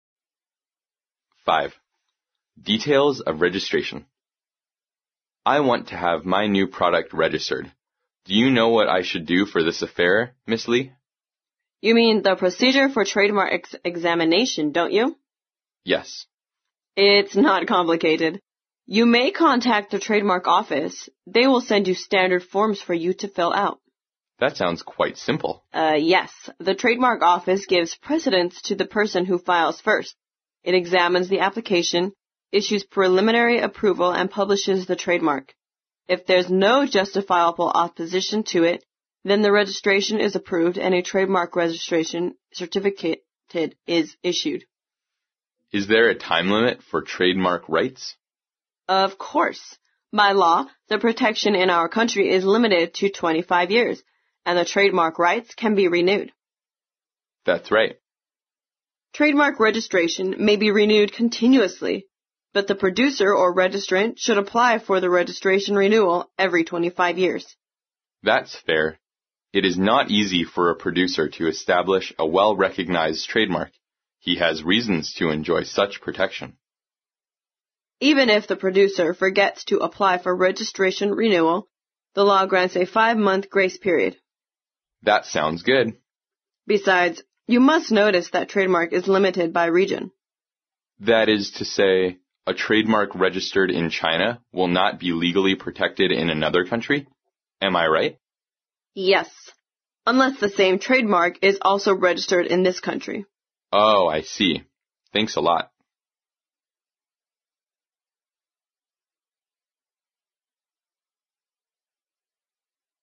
在线英语听力室外贸英语话题王 第104期:注重细节的听力文件下载,《外贸英语话题王》通过经典的英语口语对话内容，学习外贸英语知识，积累外贸英语词汇，潜移默化中培养英语语感。